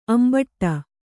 ♪ ambaṭṭa